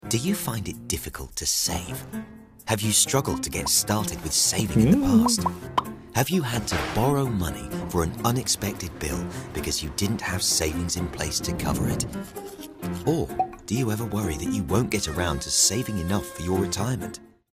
Anglais (britannique)
Polyvalent
Sympathique
Authentique